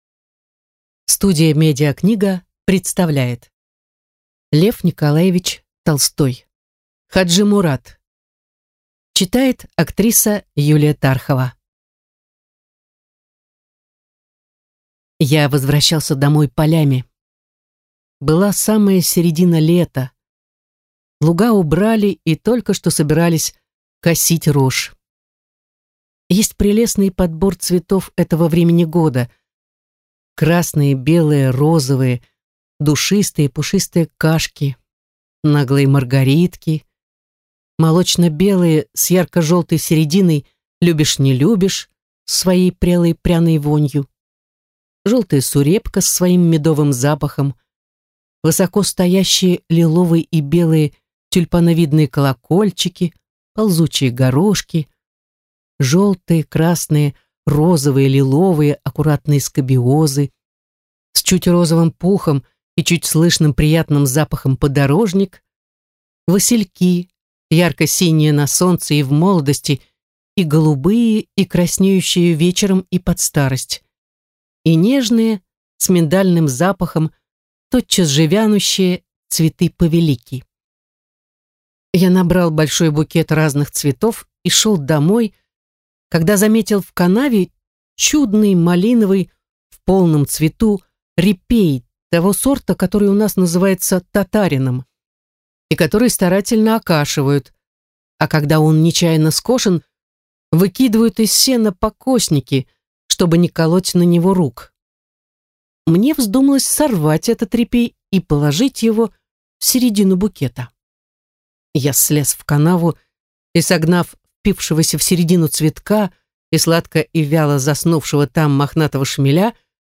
Аудиокнига Хаджи-Мурат | Библиотека аудиокниг